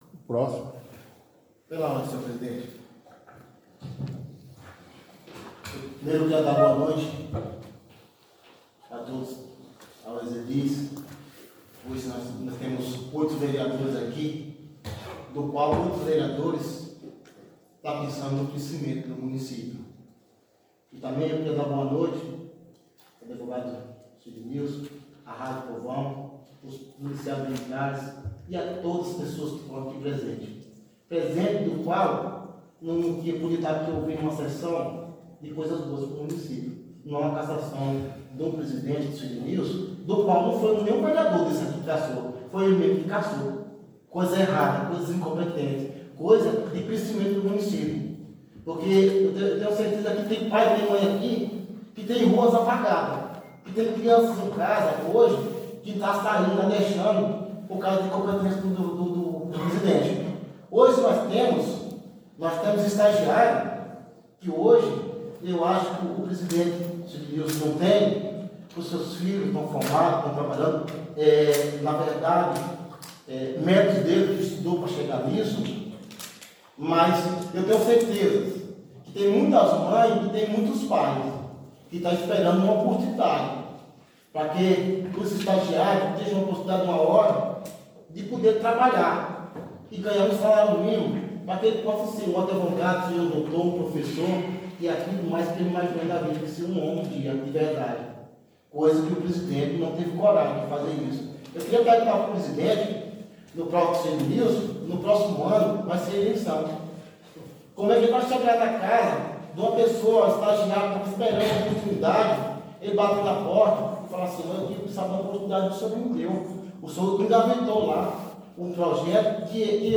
Áudio 11ª Sessão Ordinária - 04/07/2023 | Câmara Municipal de Pontes Gestal